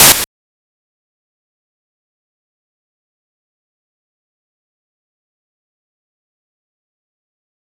snd_disappear.wav